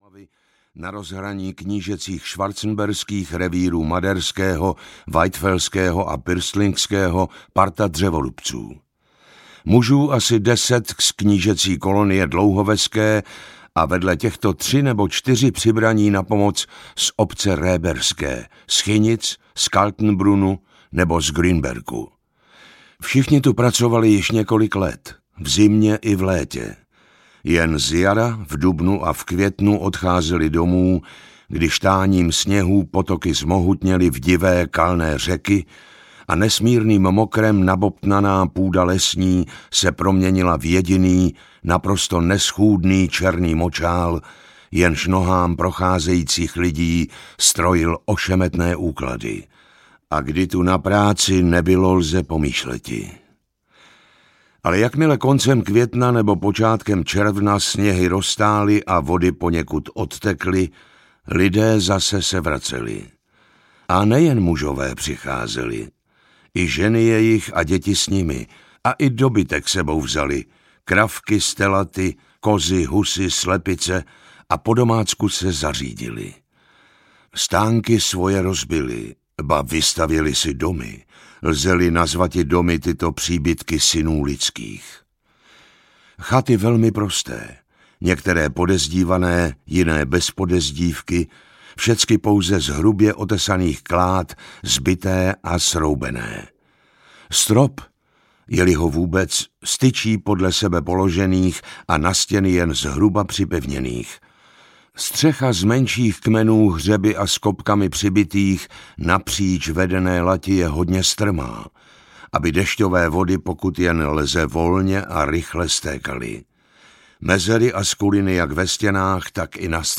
Zmizelá osada audiokniha
Ukázka z knihy